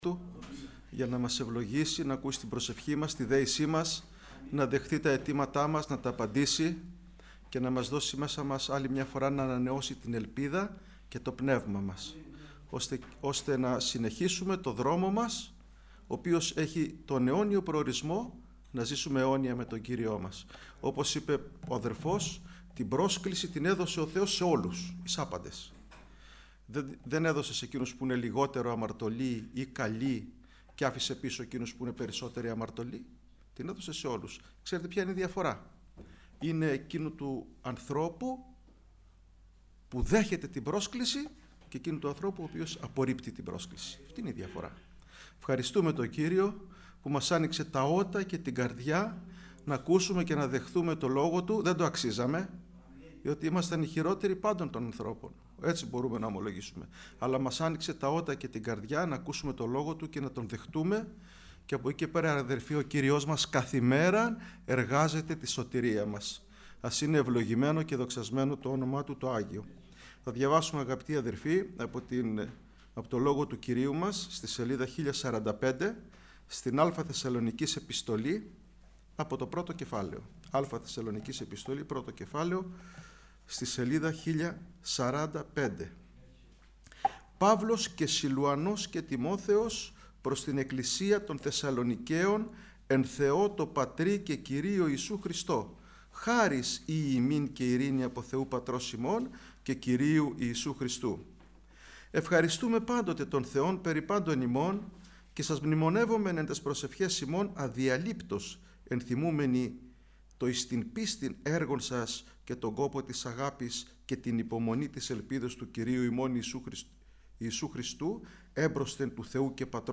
Κηρυγμ,α Ευαγγελιου